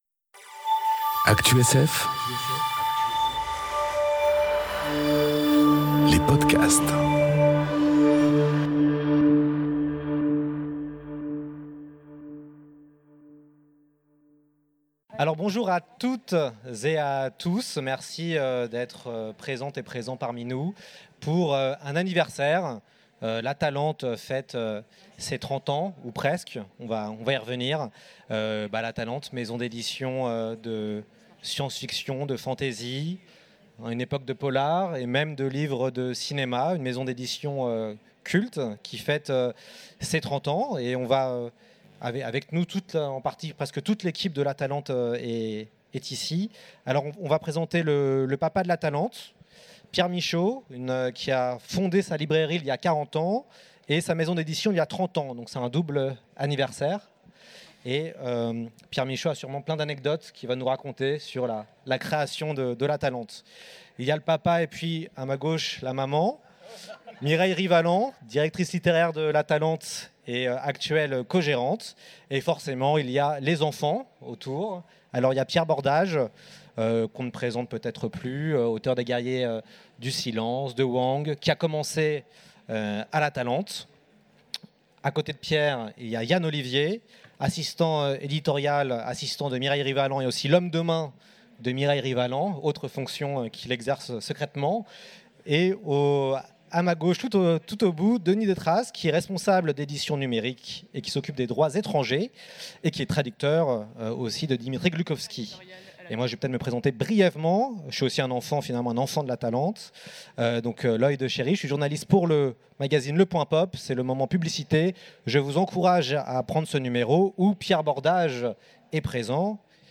Conférence Les 30 ans de l’Atalante enregistrée aux Utopiales 2018